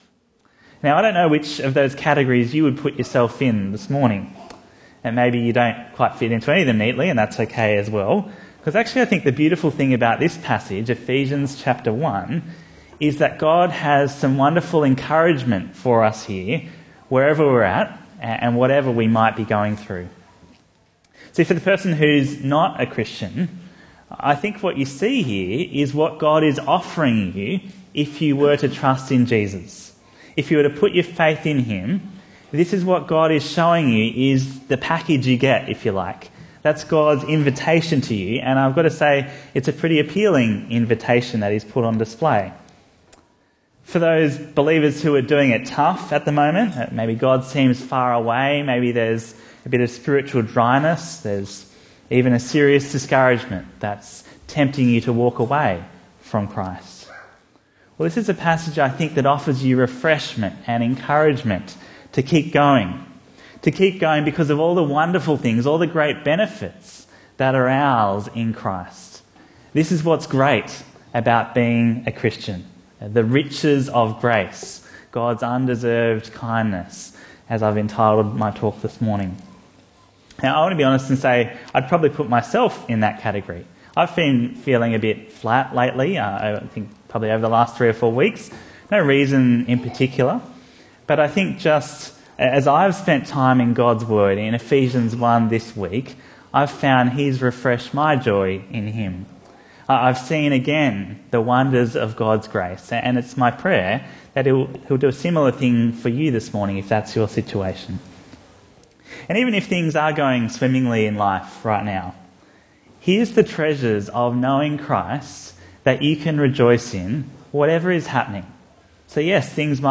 Bible Talks